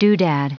Prononciation du mot doodad en anglais (fichier audio)
Prononciation du mot : doodad